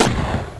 snd_30725_explode.wav